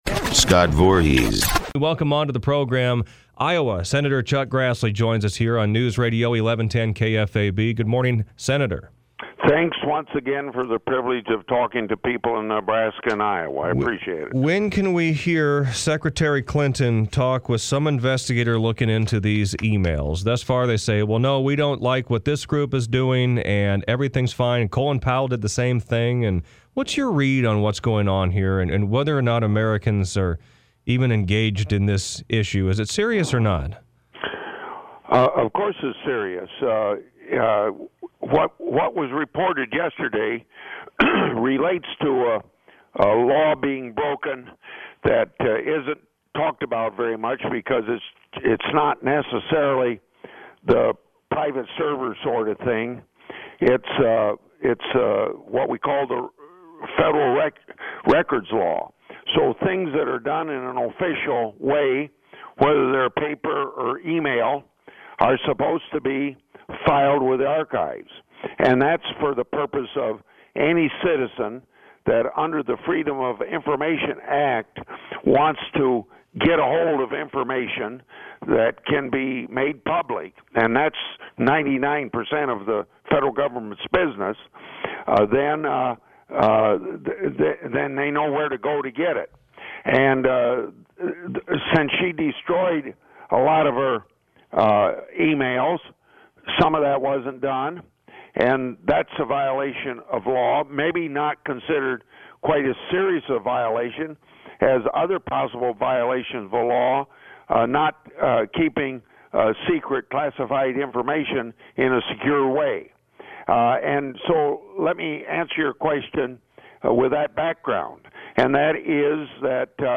Public Affairs Program, 5-26-16, KFAB.mp3